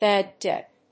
音節bàd débt